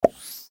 receive_message-DYKPA011.mp3